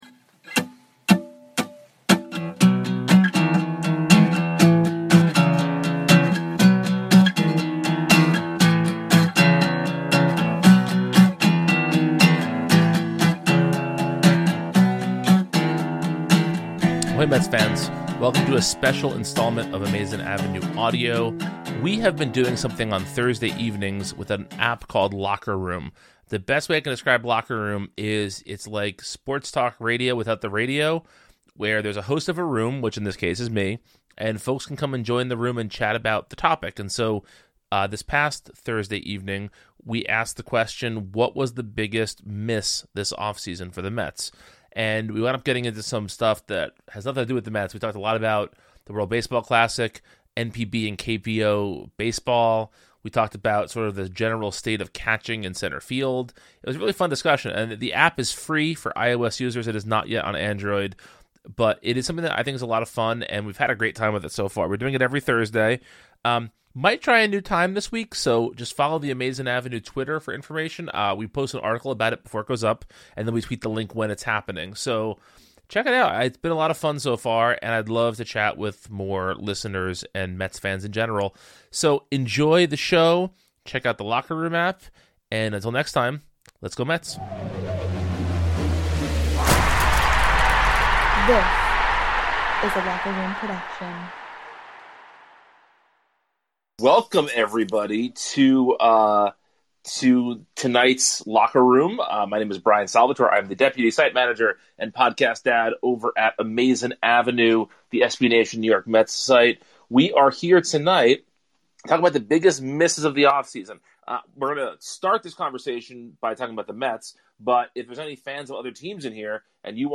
where Mets fans can have a talk-radio like experience discussing the topic of the week. This week, the room's theme was 'the biggest misses of the off-season." In addition to the usual suspects - George Springer, Jackie Bradly Jr,, and some bullpen help - the gang also discusses the lack of Asian players in Major League Baseball, the World Baseball Classic, and why Kevin Pillar and Brandon Nimmo might be a damn good platoon.